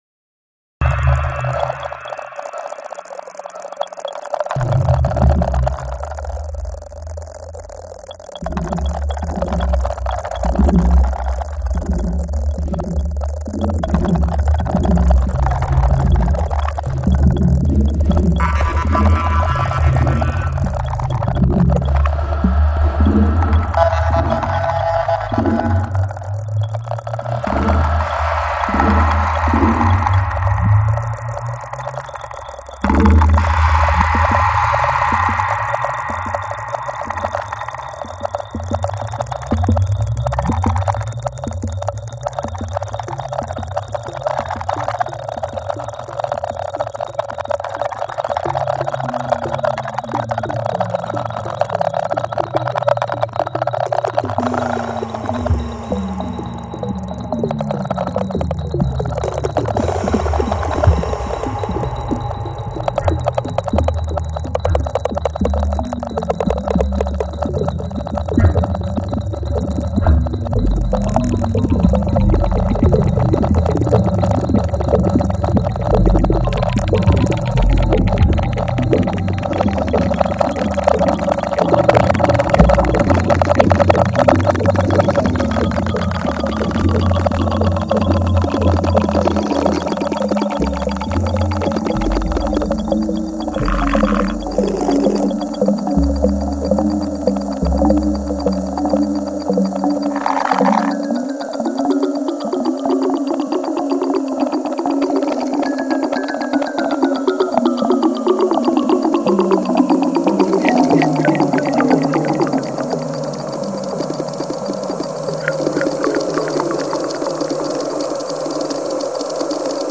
concert musique électroacoustique
Vitry sur Seine, auditorium EMA, 19h30
L’attente Une balle, jetée dans un jeu, exubérant, incohérent, semblant inarrêtable. Un moment aspirée, comme on remonte dans le temps, presque immobile, nostalgique, sur un fil, elle est en un éclair absorbée et précipitée dans un nouvel instant présent.